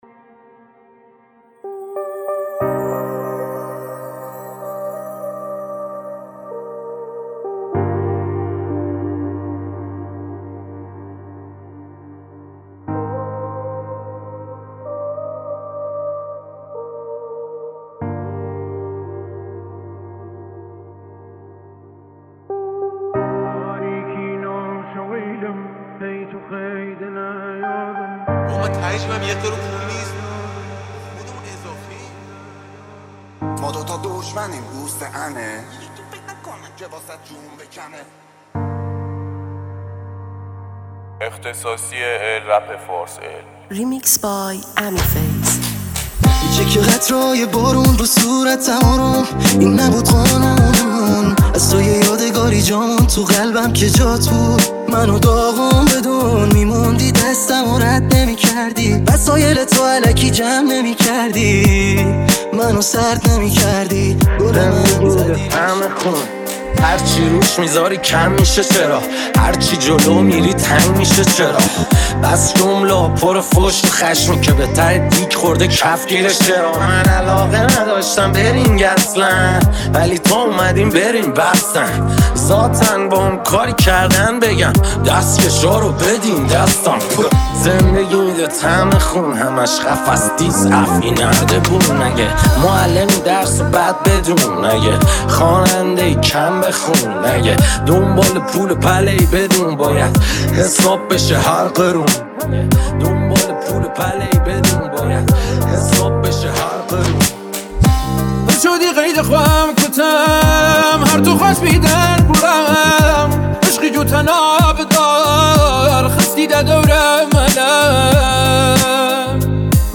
ریمیکس جدید رپ
Remix Rapi